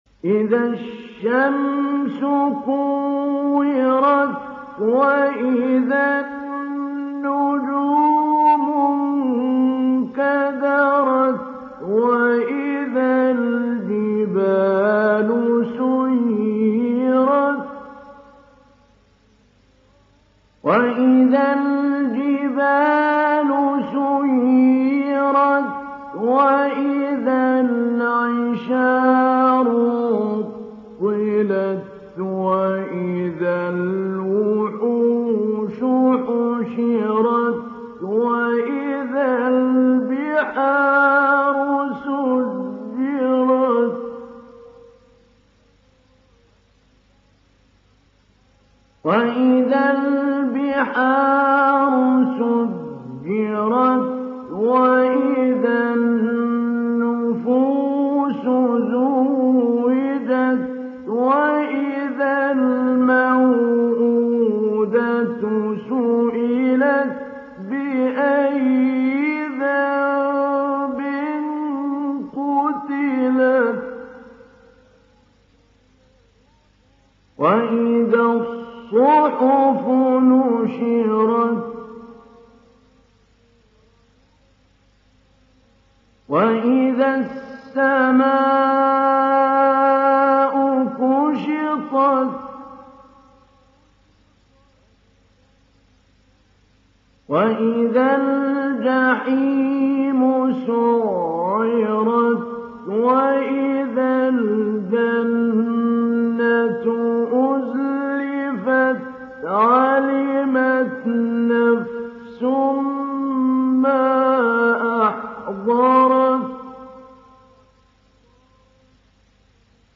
Surat At Takwir Download mp3 Mahmoud Ali Albanna Mujawwad Riwayat Hafs dari Asim, Download Quran dan mendengarkan mp3 tautan langsung penuh
Download Surat At Takwir Mahmoud Ali Albanna Mujawwad